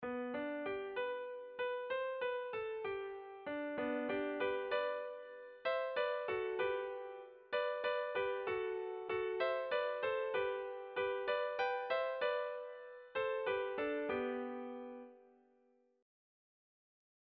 Erlijiozkoa
AB